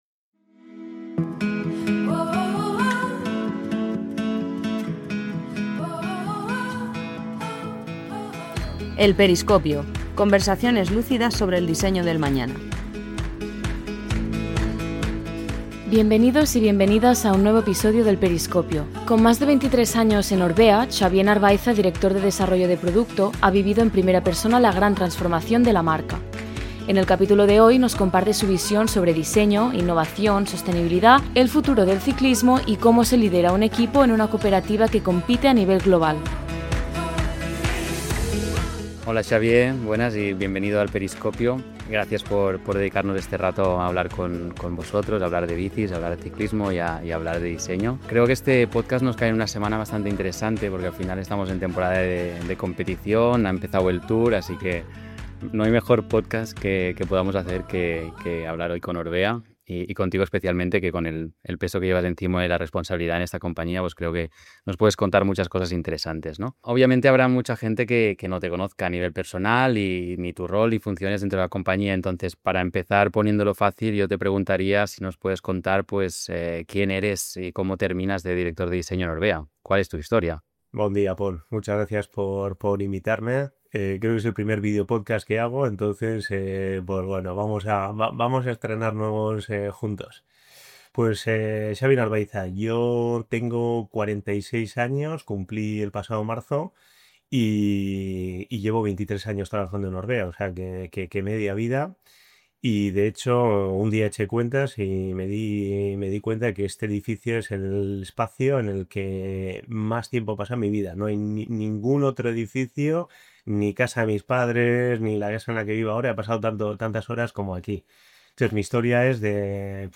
Una conversación inspiradora sobre diseño, pasión y cómo crear bicis que emocionan.